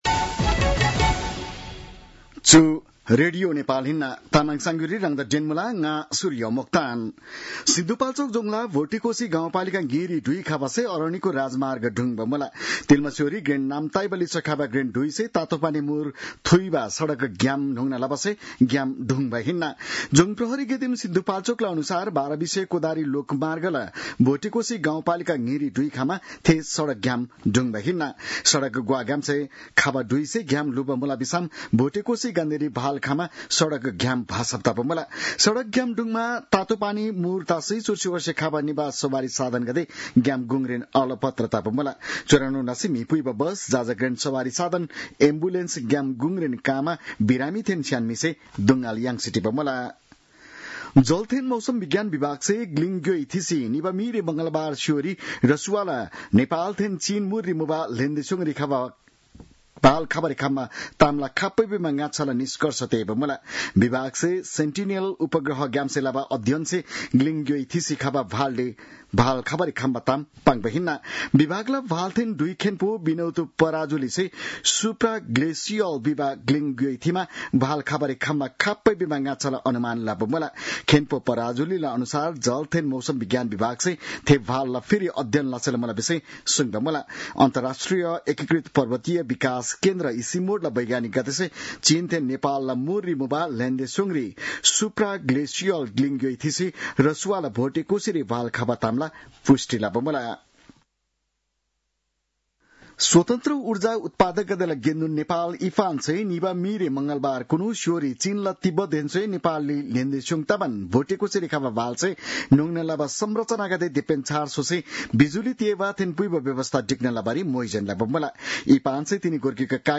तामाङ भाषाको समाचार : २६ असार , २०८२
Tamang-news-3-26.mp3